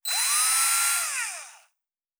pgs/Assets/Audio/Sci-Fi Sounds/Mechanical/Servo Small 2_1.wav at master
Servo Small 2_1.wav